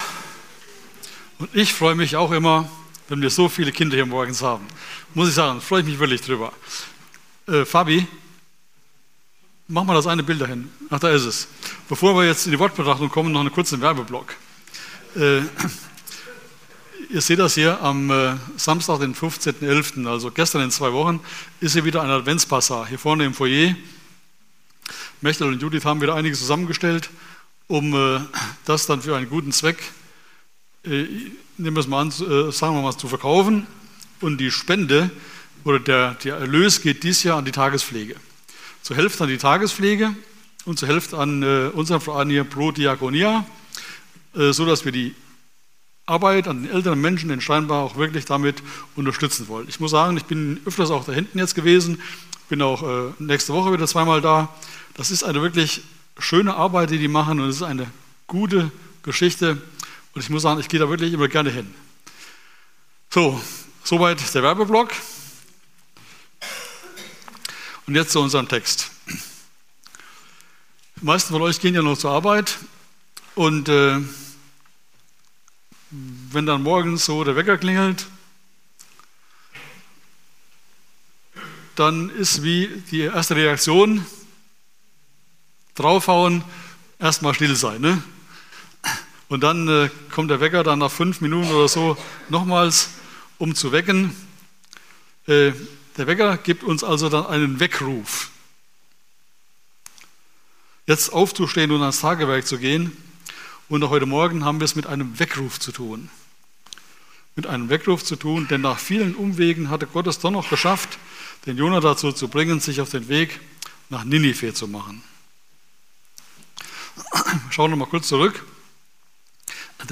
02.11.2025 Wortbetrachtung ~ Predigten - FeG Steinbach Podcast